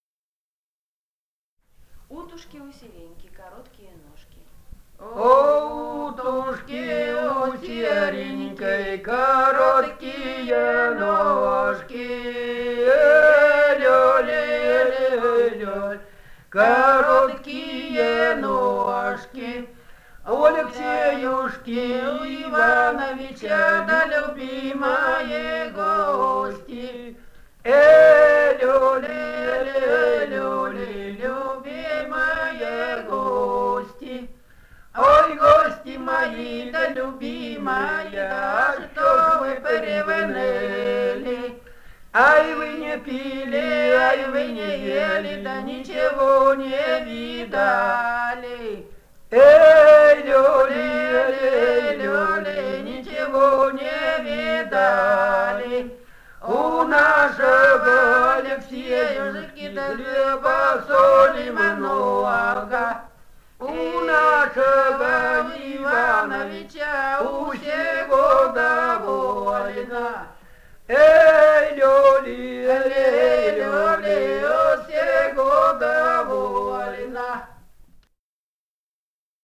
полевые материалы
Алтайский край, с. Михайловка Усть-Калманского района, 1967 г. И1001-09